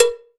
9COWBELL.wav